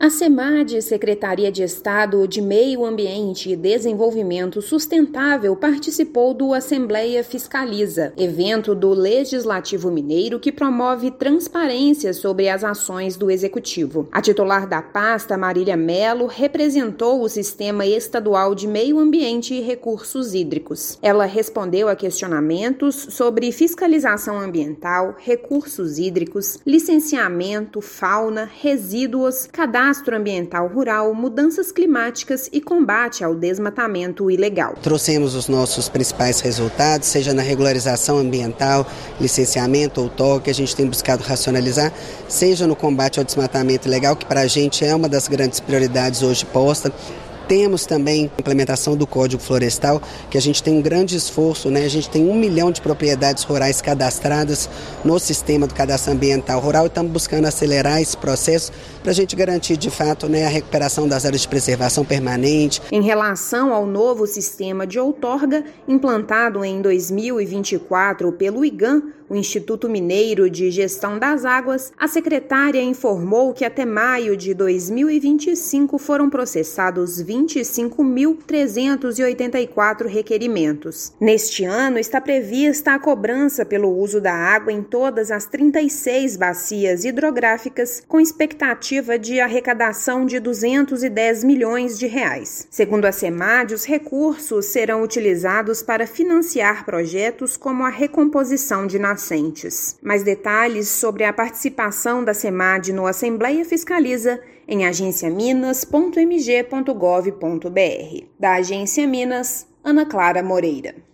Durante o Assembleia Fiscaliza, Semad apresentou avanços na gestão ambiental, recursos hídricos, licenciamento, fauna, resíduos e mudanças climáticas. Ouça matéria de rádio.